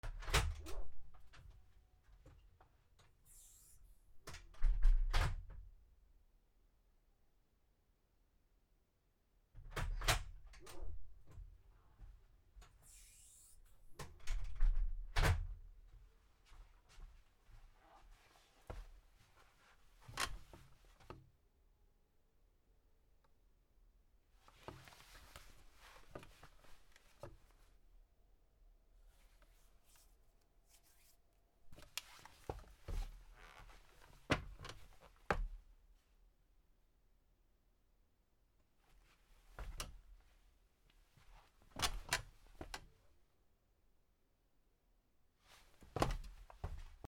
/ K｜フォーリー(開閉) / K05 ｜ドア(扉)
ピアノ室に入って椅子に座り足を組む・イスに立って下りる・また座った後勢い良く立つ